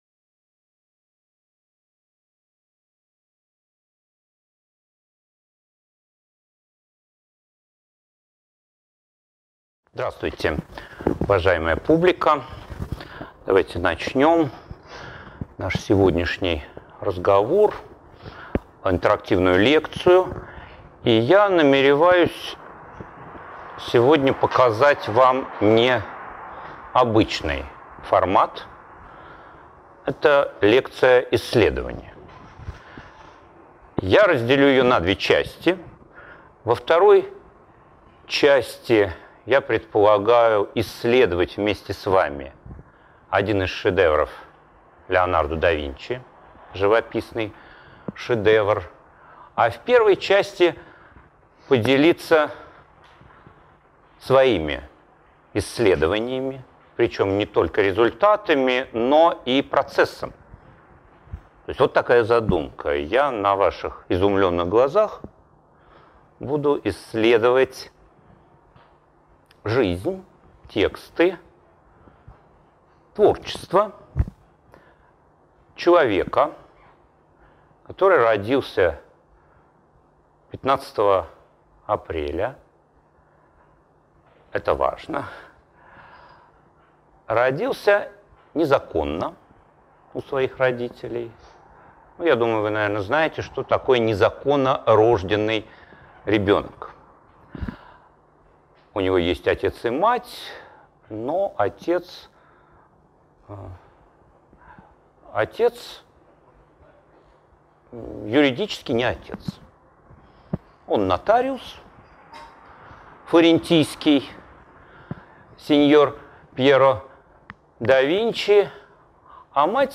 Аудиокнига Метод Леонардо да Винчи: создание совершенных произведений | Библиотека аудиокниг